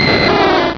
Cri de Draco dans Pokémon Rubis et Saphir.